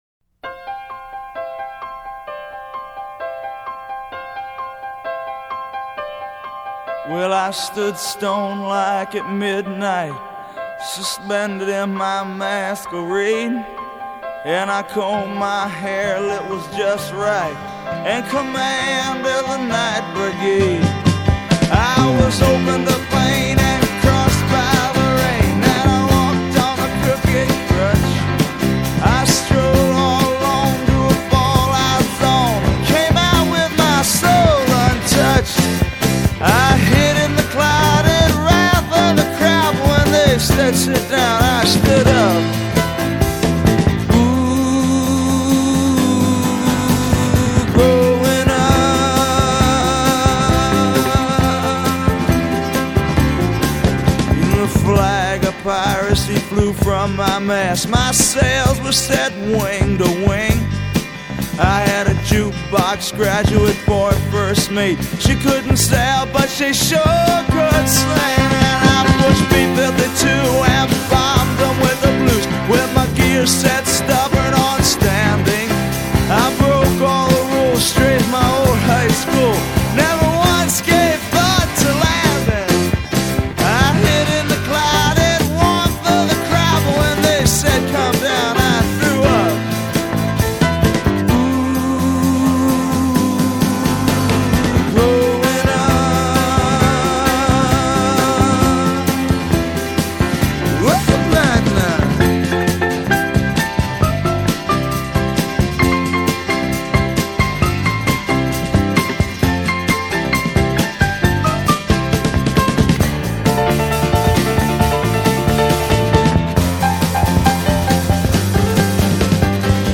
Rock, Folk Rock